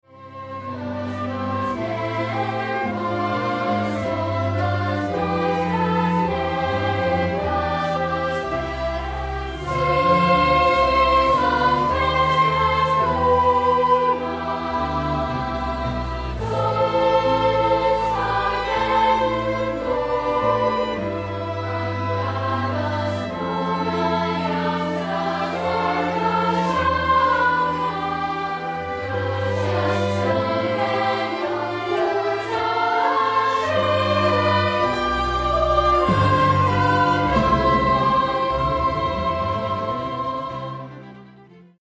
a l'AUDITORI